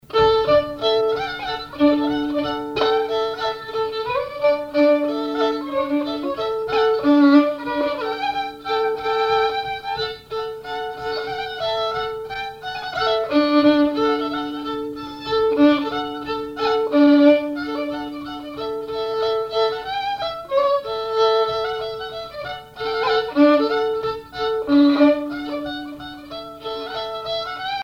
violoneux, violon,
danse : java
instrumentaux au violon mélange de traditionnel et de variété
Pièce musicale inédite